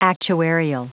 Prononciation du mot actuarial en anglais (fichier audio)
Prononciation du mot : actuarial